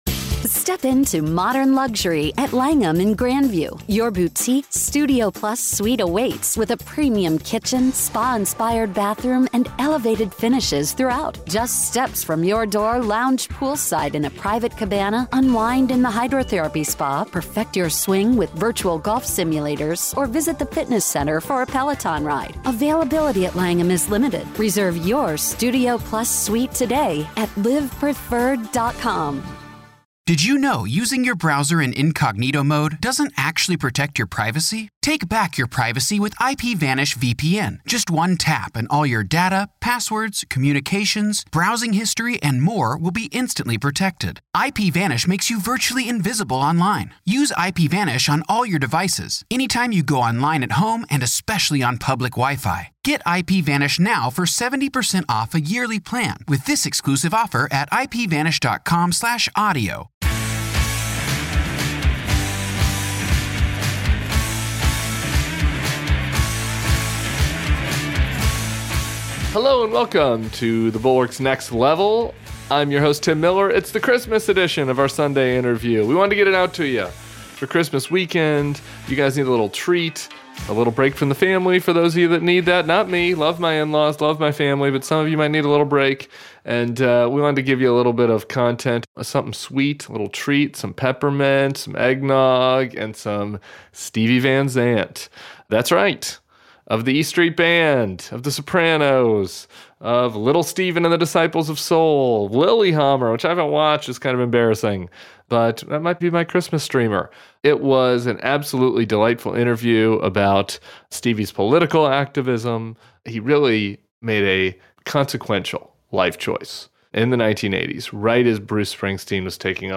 Get the scoop on his music journey, genre-blending tricks, and thoughts on race in the industry. Plus, he spills the beans on acting, 'The Sopranos,' and how TV shows mix with his tunes. Don't miss the stories and laughs in this killer interview!"